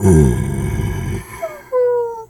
bear_pain_whimper_04.wav